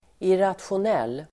Ladda ner uttalet
irrationell adjektiv, irrational Uttal: [iratsjon'el:] Böjningar: irrationellt, irrationella Definition: svår att logiskt förklara, oberäknelig illogical adjektiv, ologisk , irrationell irrational adjektiv, irrationell